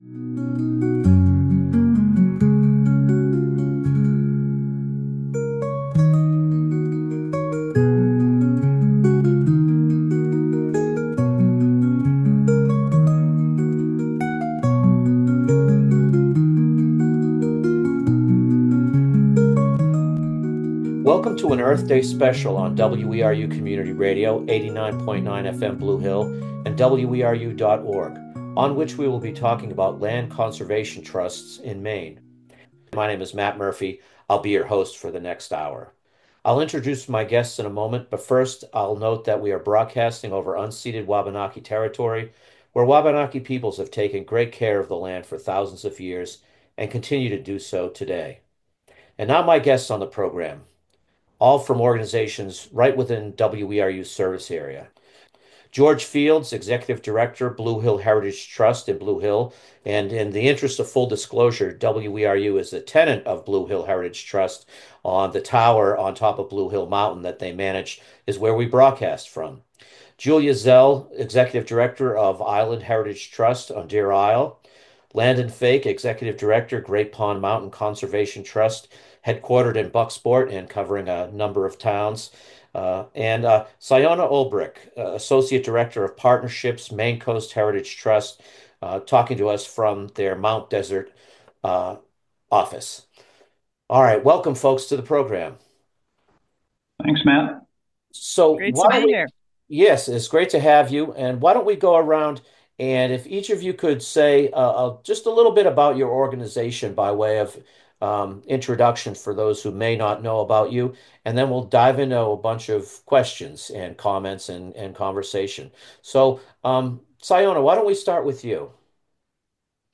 On Earth Day 2025, listeners hear from representatives from four Maine conservation land trust organizations about their philosophies, challenges, and current/upcoming projects.